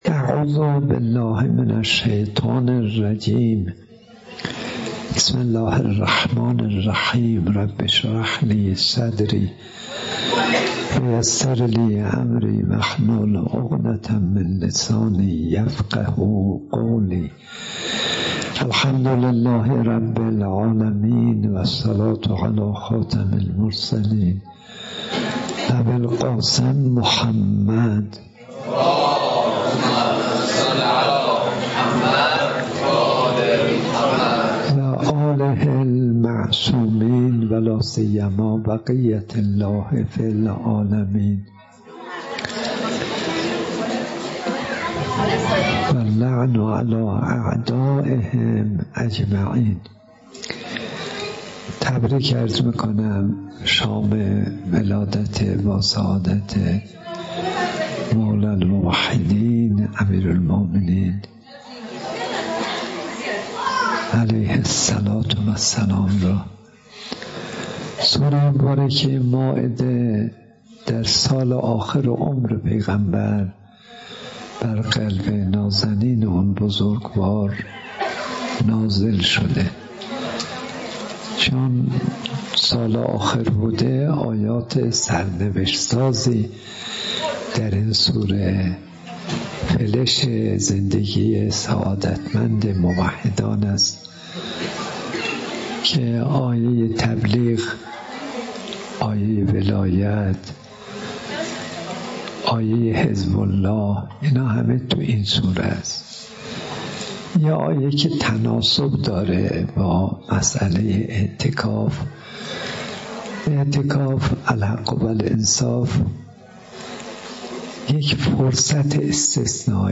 سخنرانی به مناسبت عید غدیر